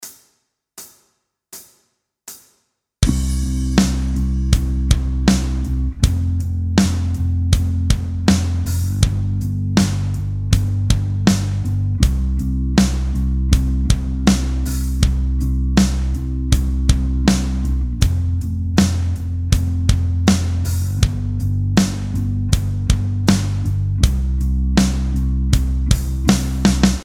Backing-Track-6-1.mp3